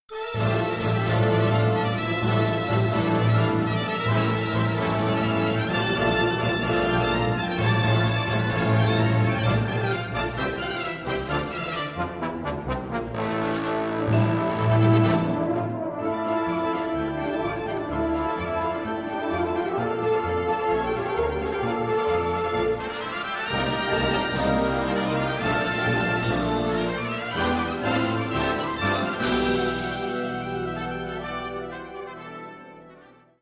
Categoría Banda sinfónica/brass band
Instrumentación/orquestación Ha (banda de música)